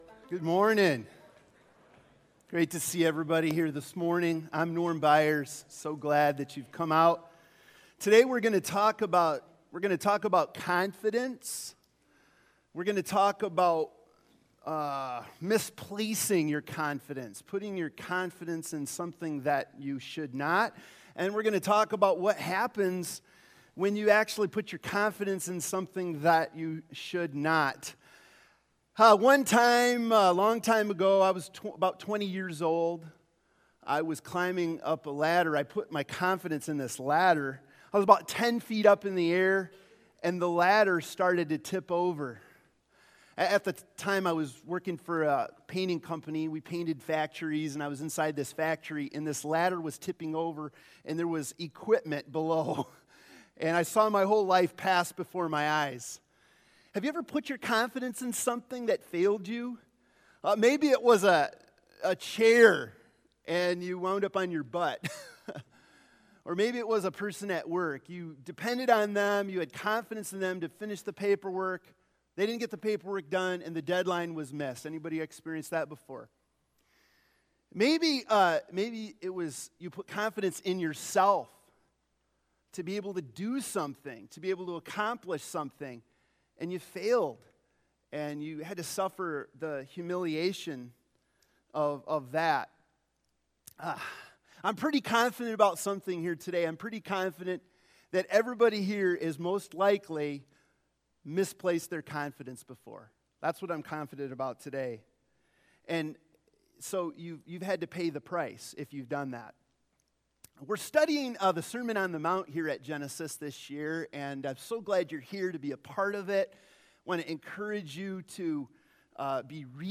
Service Type: Sunday Morning Preacher